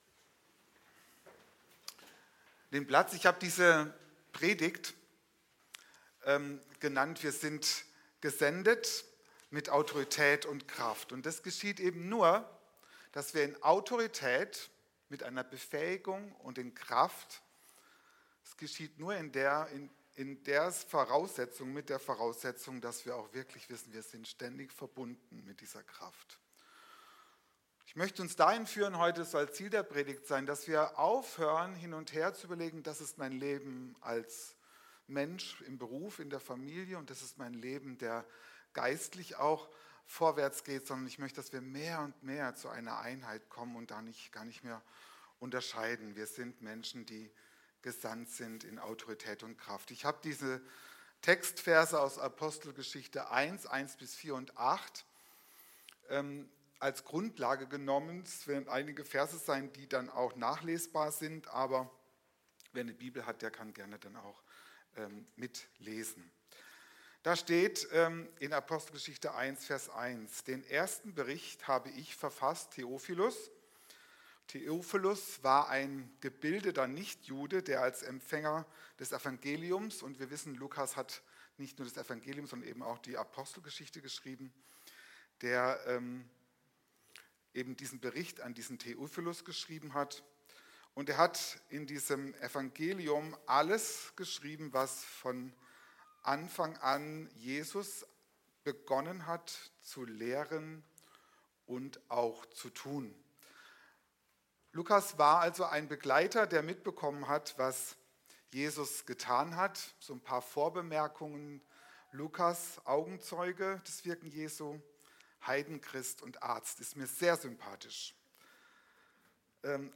Kategorie Predigten